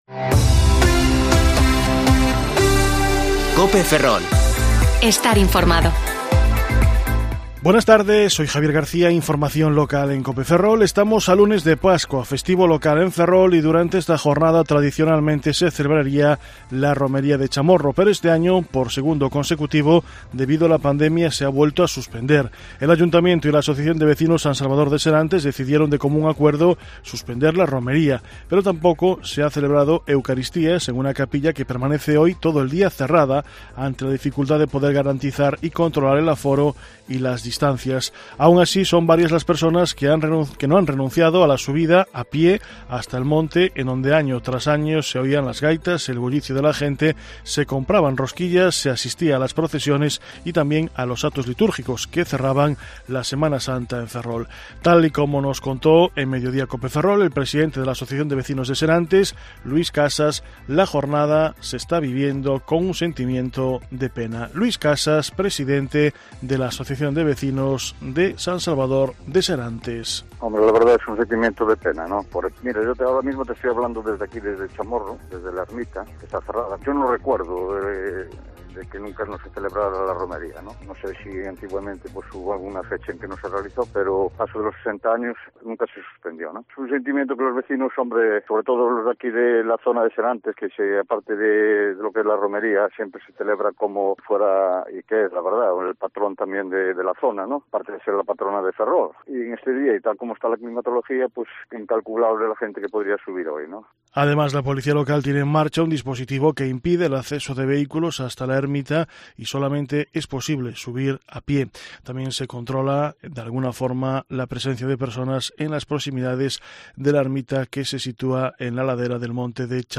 Informativo Mediodía COPE Ferrol 5/4/2021 (De 14,20 a 14,30 horas)